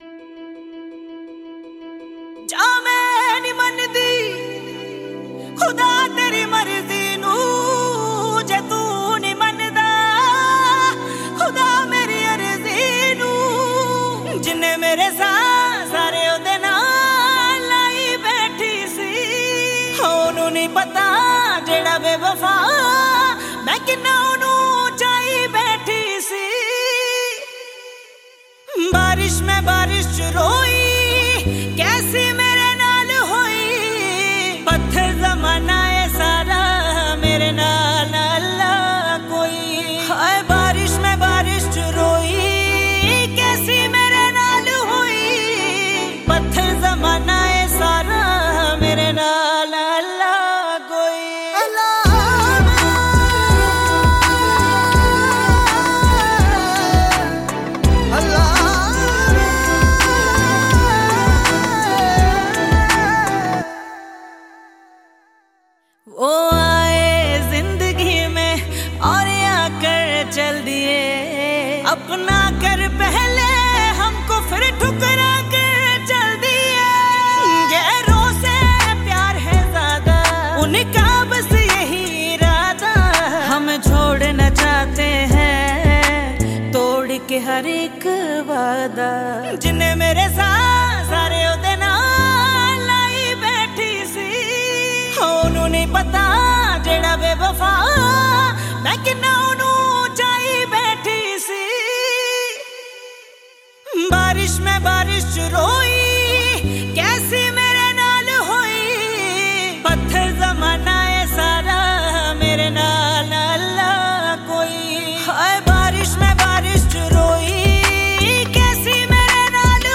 Category: Punjabi Album